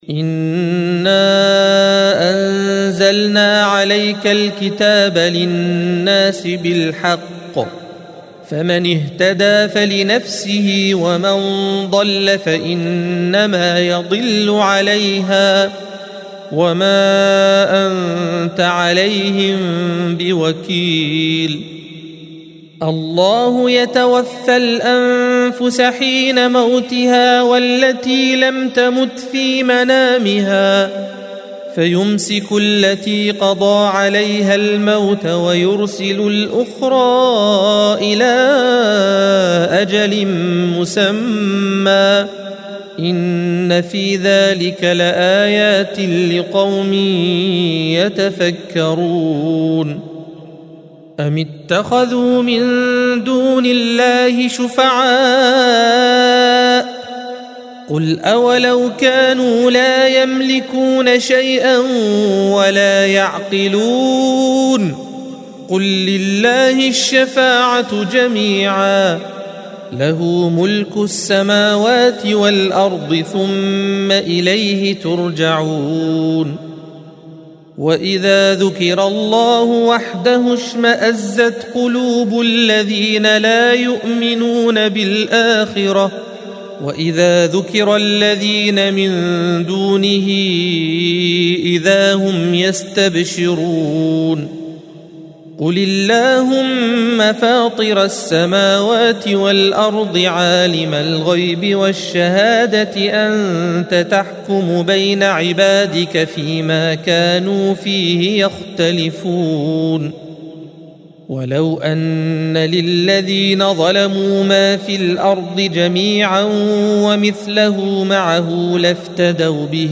الصفحة 463 - القارئ